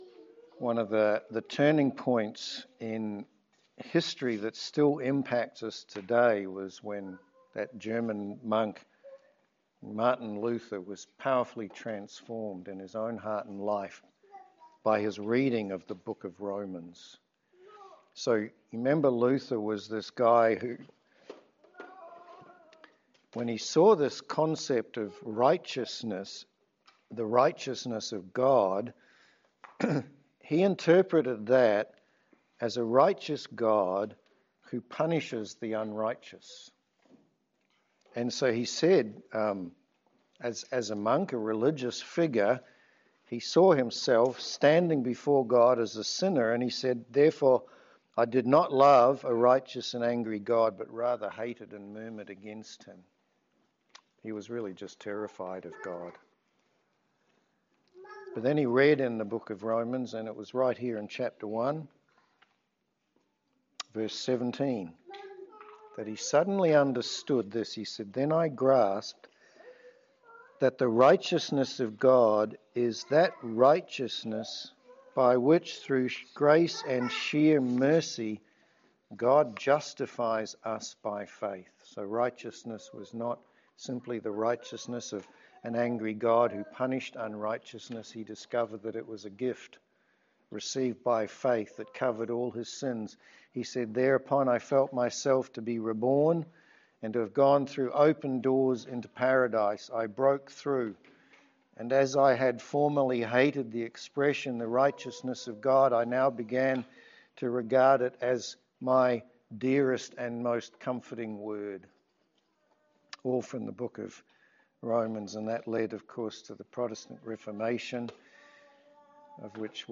Romans 1:1-7 Service Type: Sermon This week we are beginning a sermon series in the book of Romans.